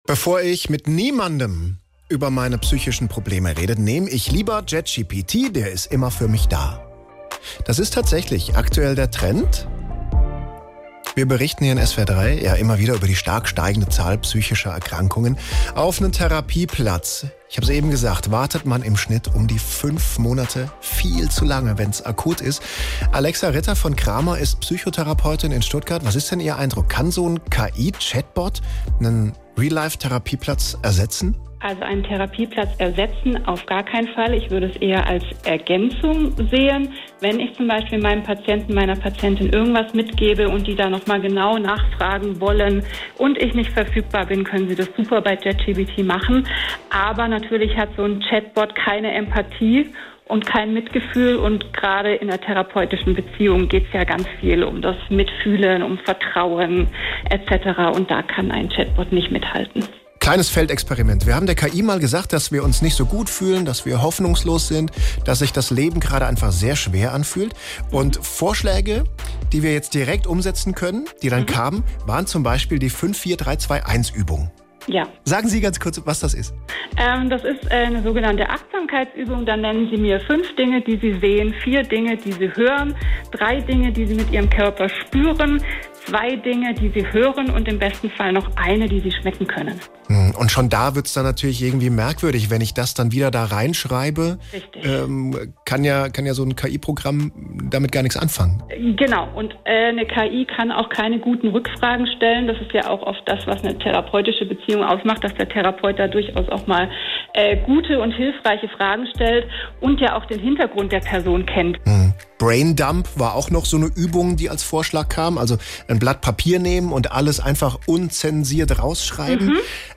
SWR3 PUSH Interview: Kann KI bei psychischen Problemen helfen?